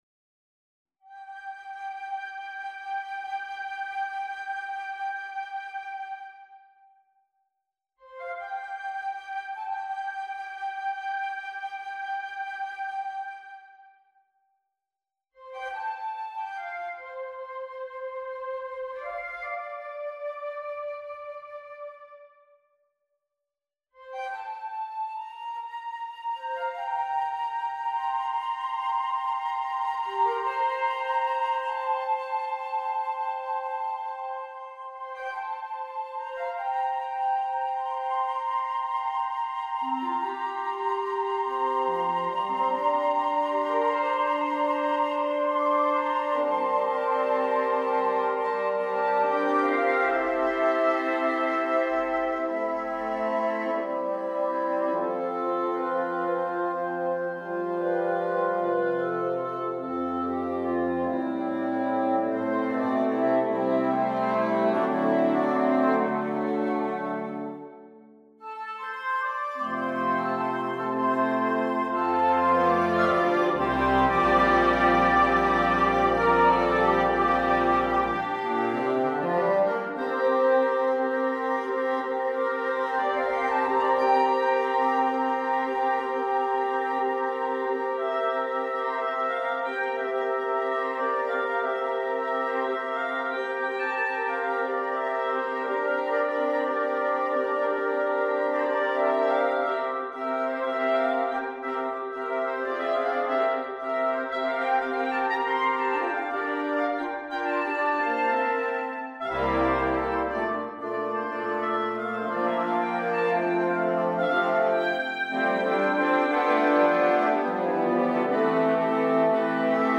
for wind dectet (double wind quintet) & optional percussion
AUDIO ONLY (*with Percussion*)